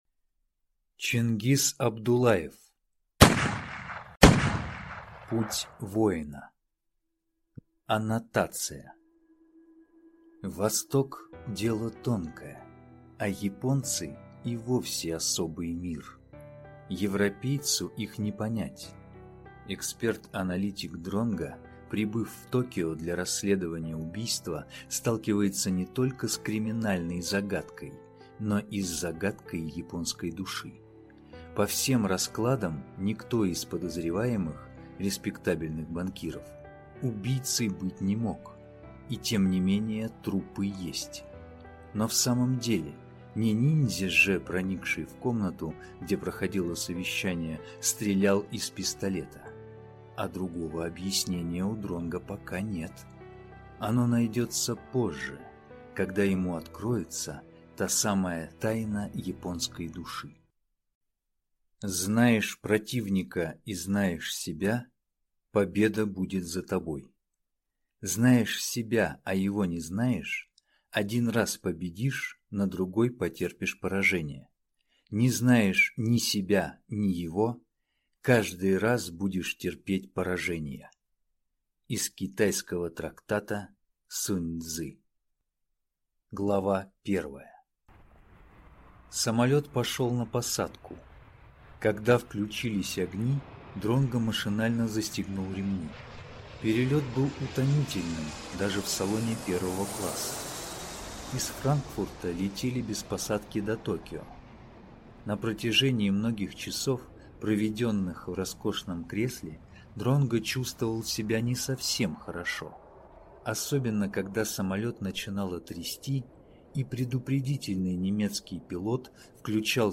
Аудиокнига Путь воина | Библиотека аудиокниг